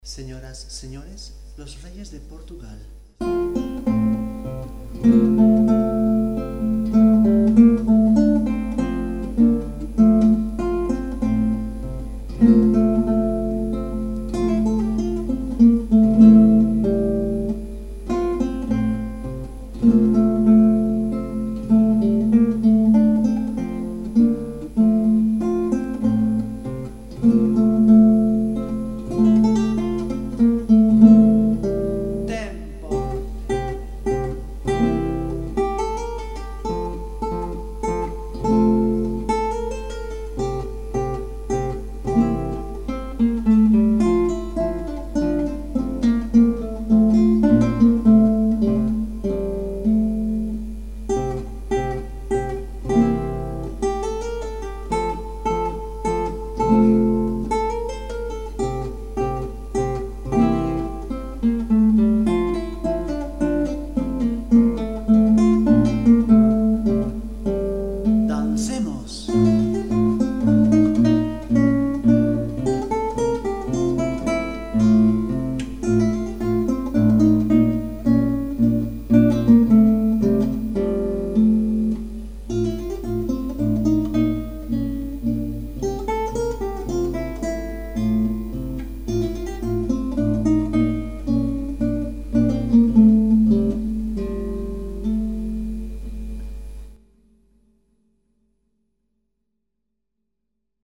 Audios Clásicos